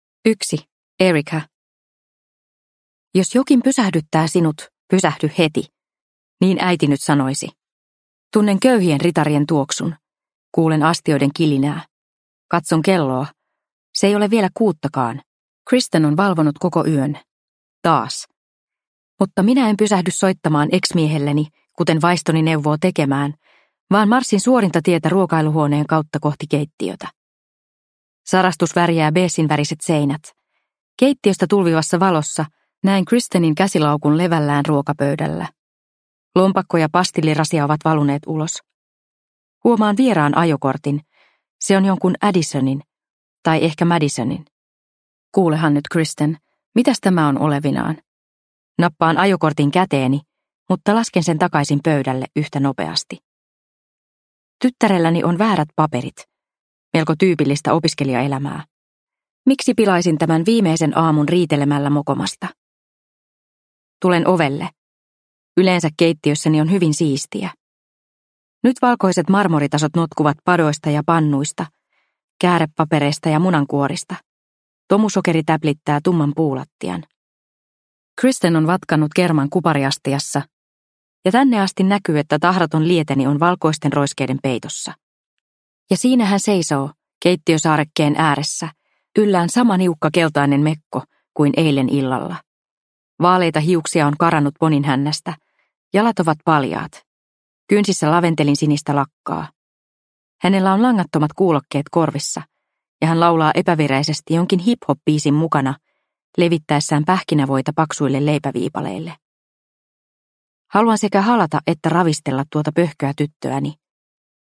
Sanoja sydämestä – Ljudbok – Laddas ner